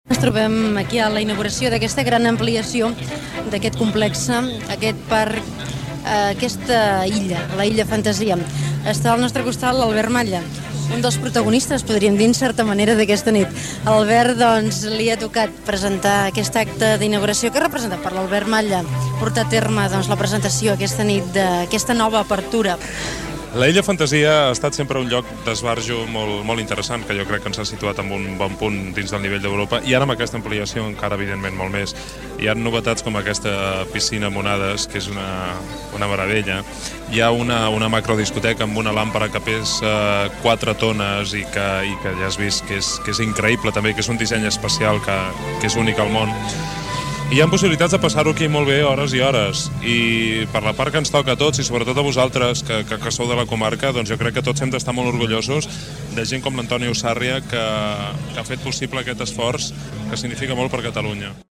Entrevista
Banda FM